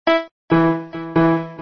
piano nē 74
piano74.mp3